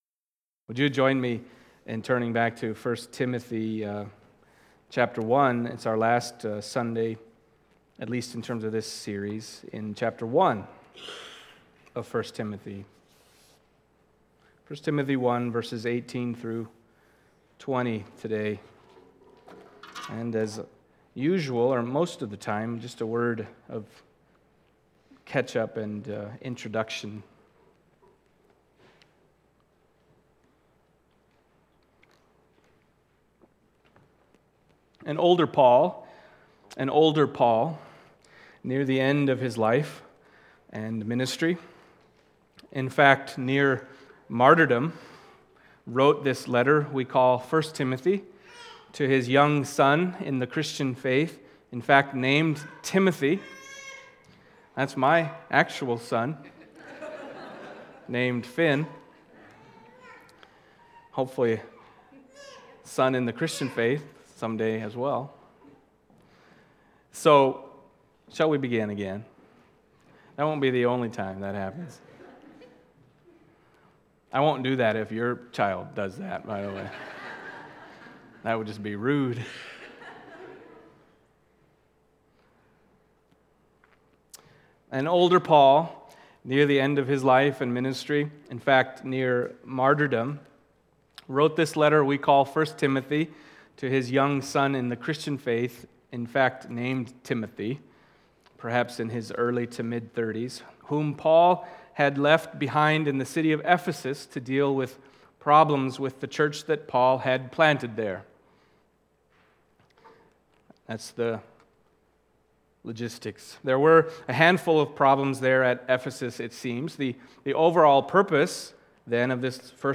Passage: 1 Timothy 1:18-20 Service Type: Sunday Morning